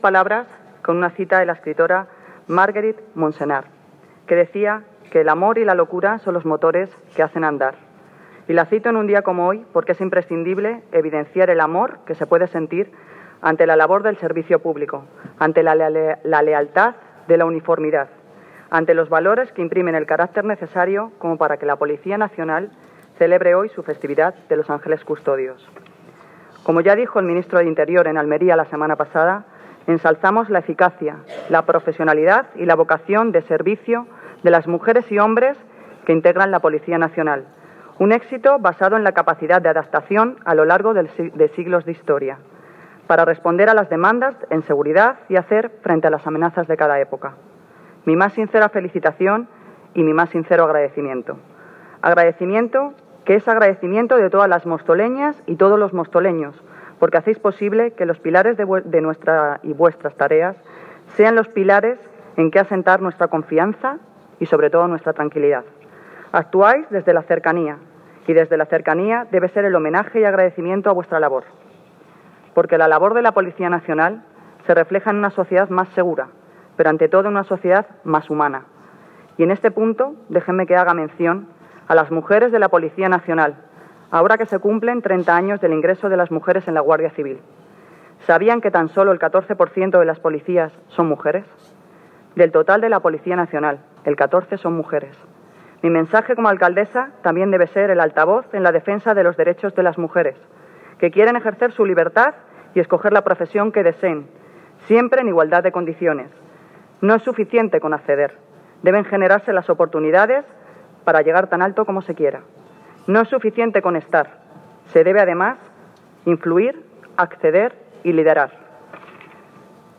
Audio - Noelia Posse (Alcaldesa de Móstoles) Sobre Día Policía